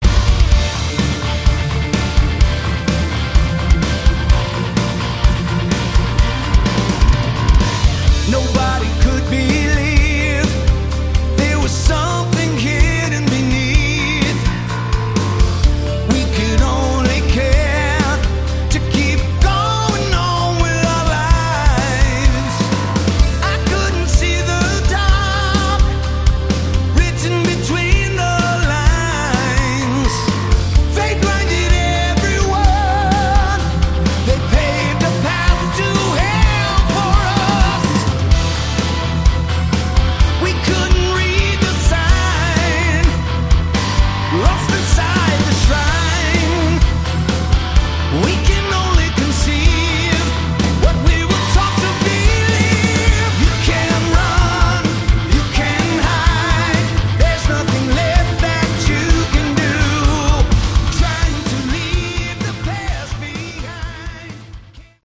Category: AOR / Melodic Hard Rock
vocals
guitar
keyboards
bass
drums